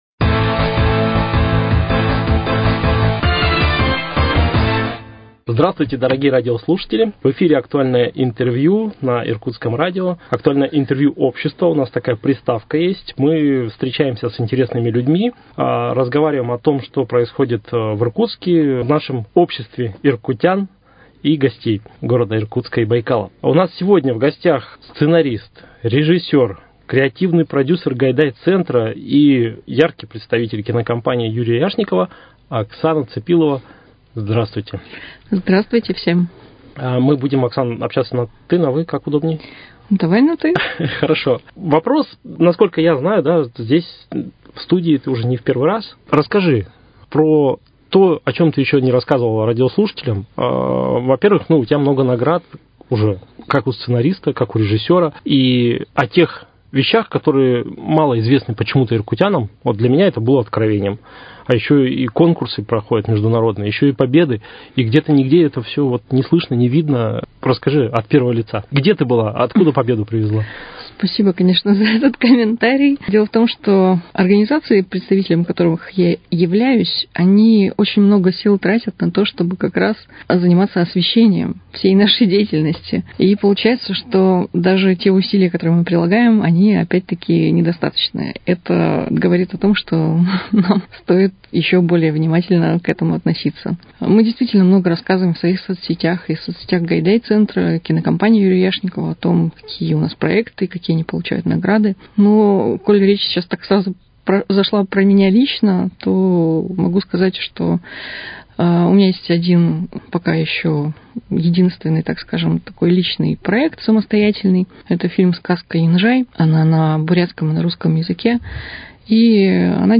Актуальное интервью.